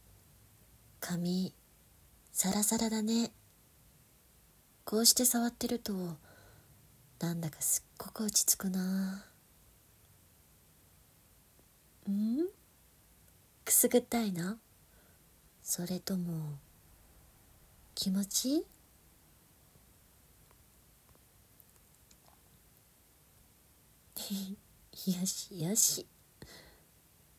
優しい彼女に膝枕をしてもらい、髪を撫でられながら甘く囁かれるシチュエーションボイスです。